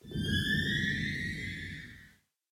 cave10.mp3